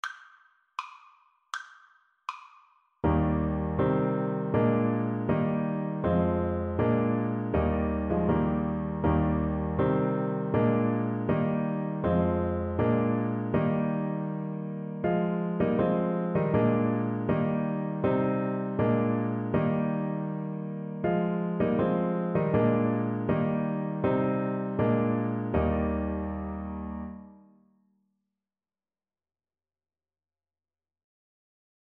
Trumpet
Eb major (Sounding Pitch) F major (Trumpet in Bb) (View more Eb major Music for Trumpet )
Andante
2/4 (View more 2/4 Music)
Bb4-Bb5
Traditional (View more Traditional Trumpet Music)
Israeli